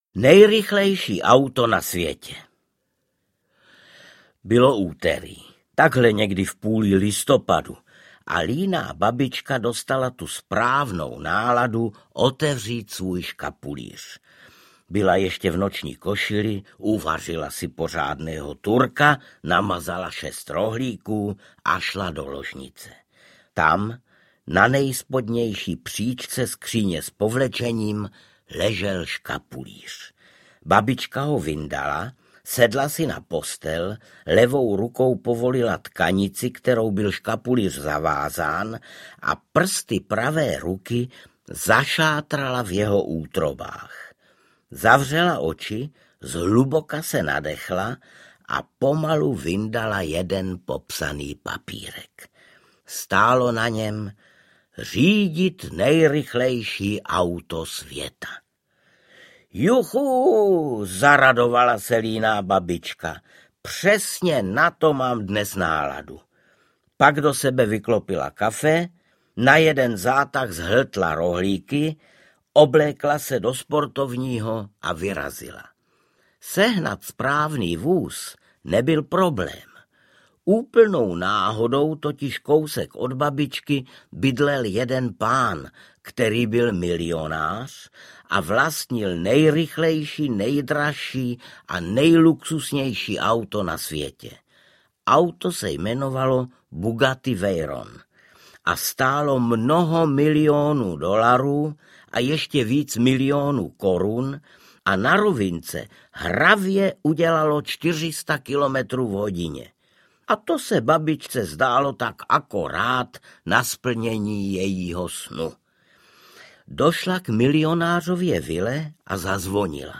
O líné babičce audiokniha
Ukázka z knihy
• InterpretArnošt Goldflam